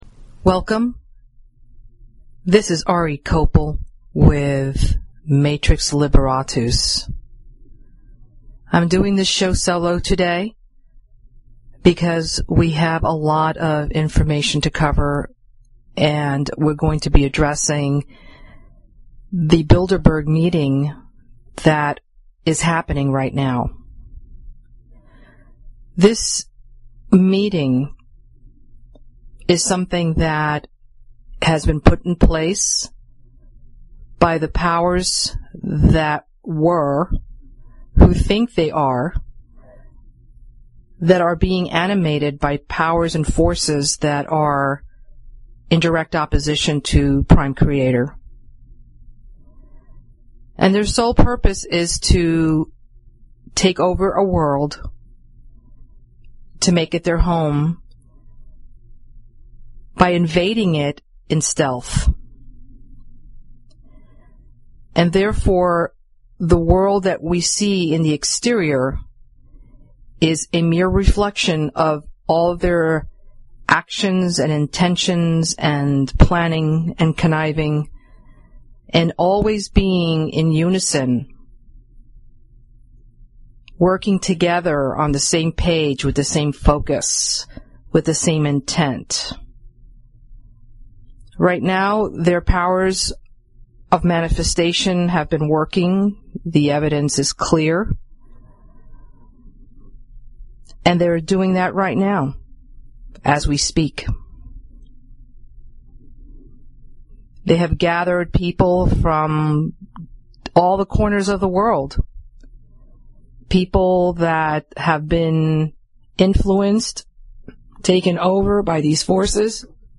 Talk Show Episode, Audio Podcast, Matrix_Liberatus and Courtesy of BBS Radio on , show guests , about , categorized as
Raw & uncensored talk about shattering the Matrix